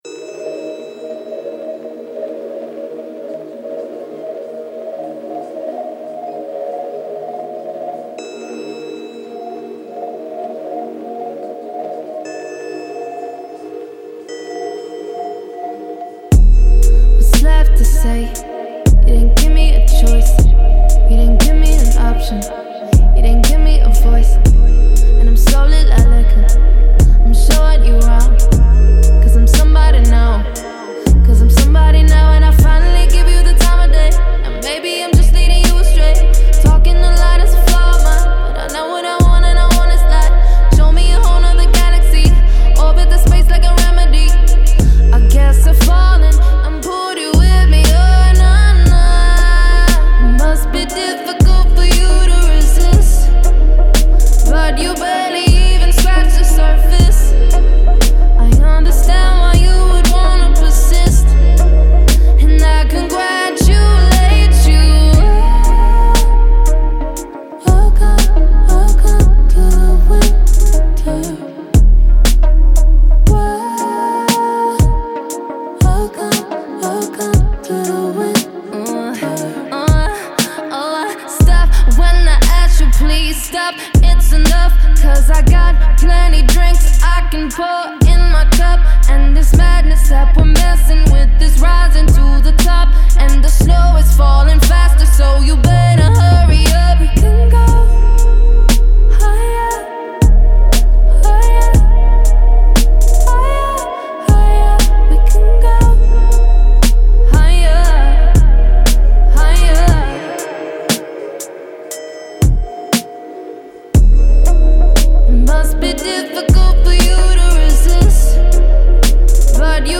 Мягкий БАС в МАШИНУ , СПОКОЙНАЯ МУЗЫКА в МАШИНУ